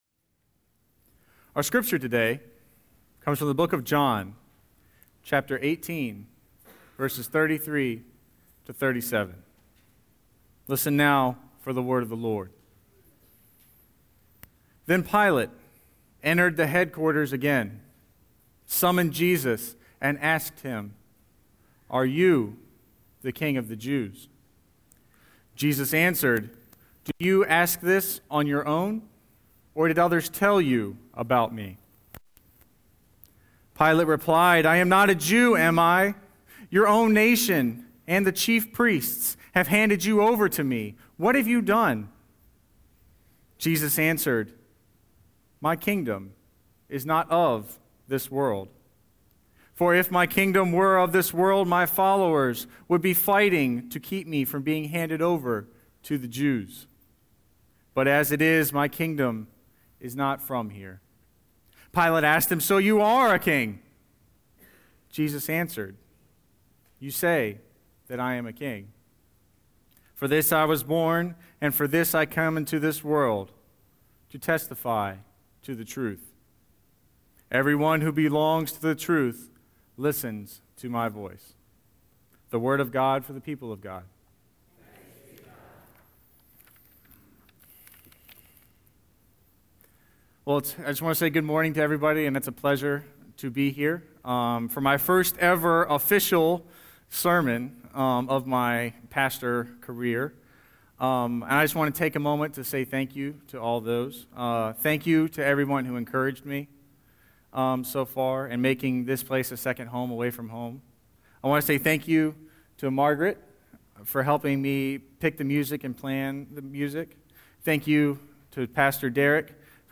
Listen to this week’s Scripture and Sermon
11-22-Scripture-and-Sermon.mp3